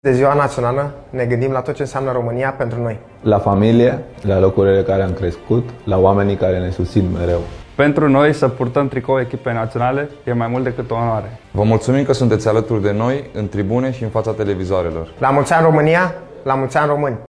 Și fotbaliștii echipei naționale a României au transmis un mesaj de 1 Decembrie. În videoclip apar Ianis Hagi, Andrei Rațiu, Dennis Man, Răzvan Marin, Marius Marin și Denis Drăguș.